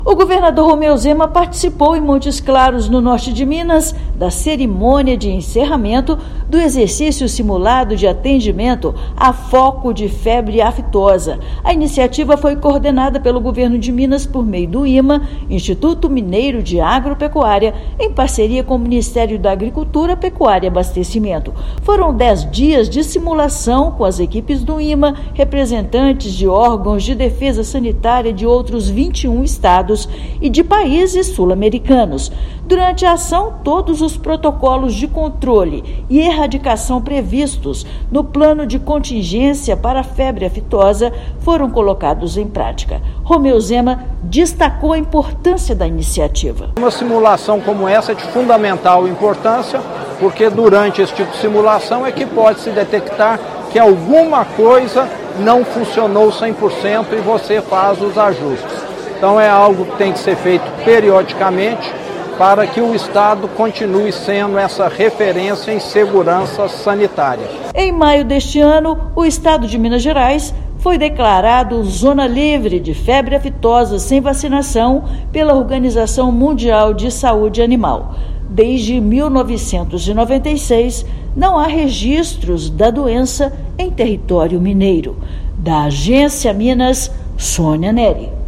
Nos dez dias da ação, todos os protocolos de controle e erradicação previstos no plano de contingenciamento, nos níveis tático e operacional, foram colocados em prática. Ouça matéria de rádio.